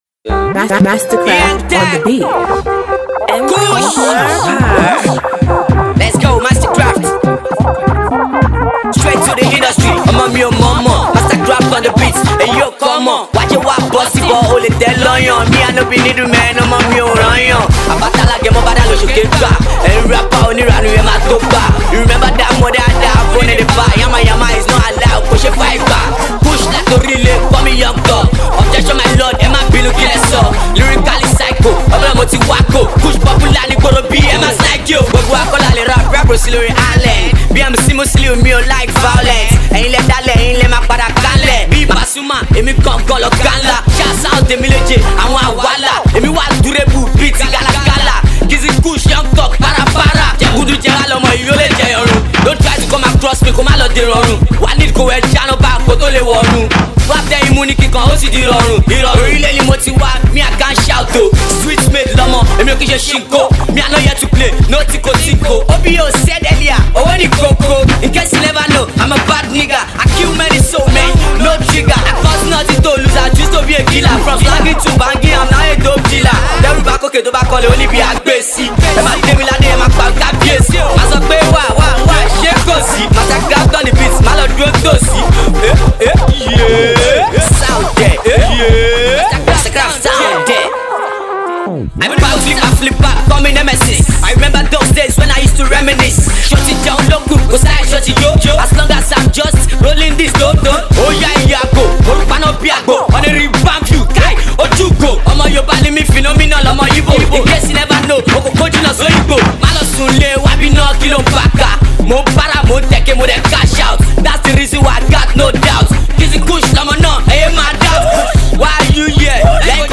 Banging Rap song
Street Rap song